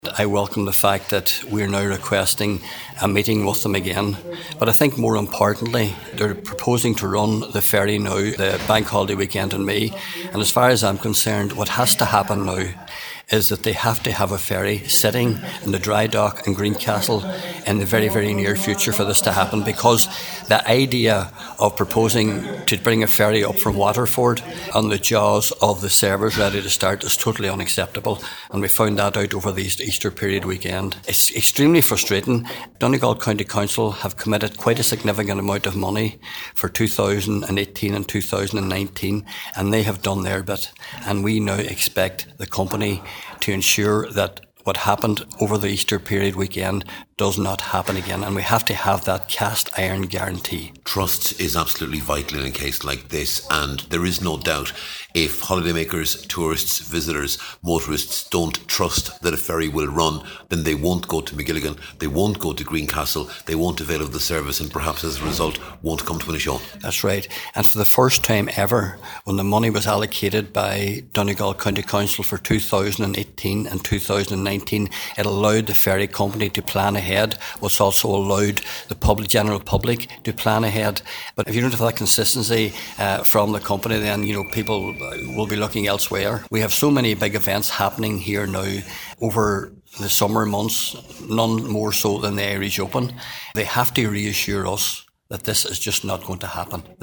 Cllr Martin Farren says cast iron guarantees are now needed for the summer: